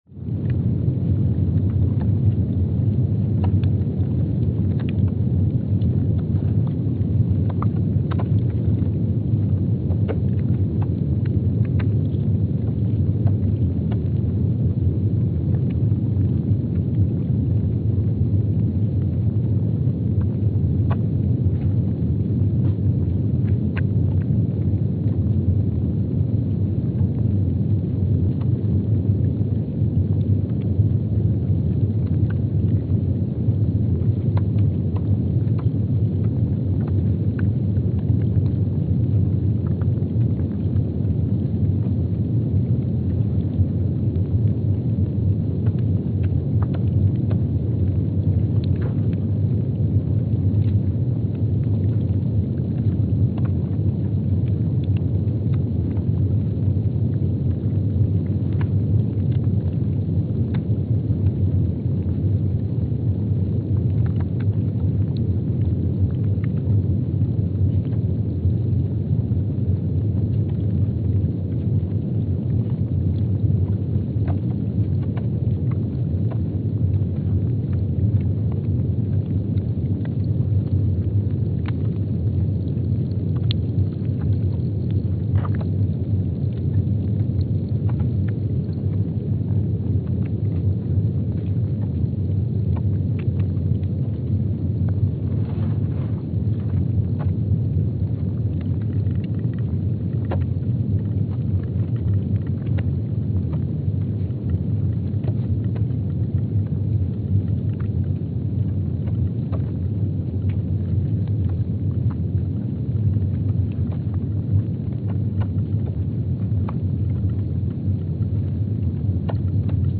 Palmer Station, Antarctica (seismic) archived on June 5, 2025
Station : PMSA (network: IRIS/USGS) at Palmer Station, Antarctica
Speedup : ×500 (transposed up about 9 octaves)
Loop duration (audio) : 05:45 (stereo)
Gain correction : 25dB